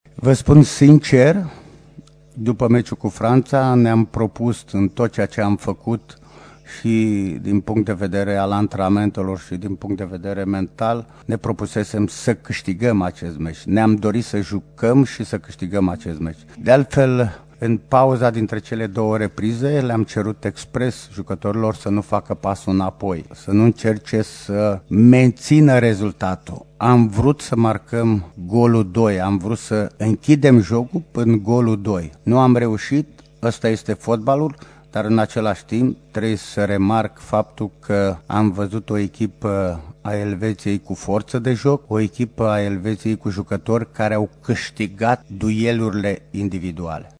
Despre meciul de ieri vorbeşte în continuare selecţionerul României, Anghel Iordănescu.